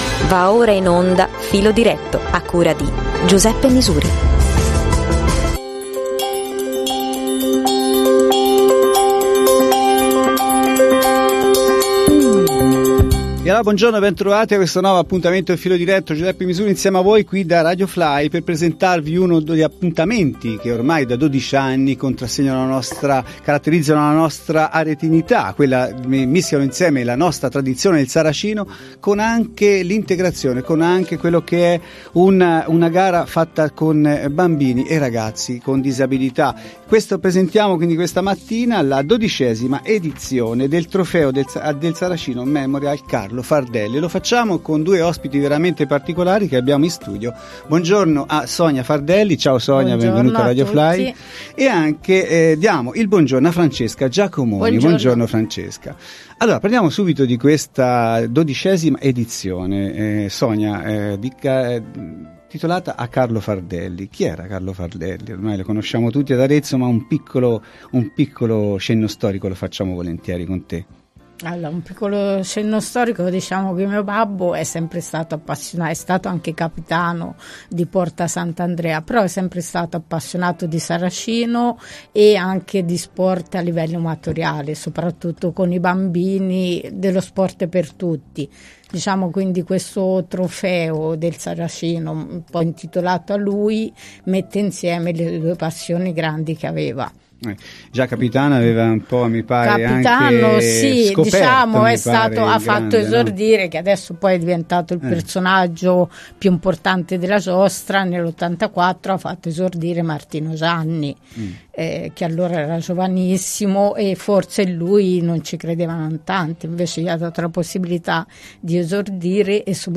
In studio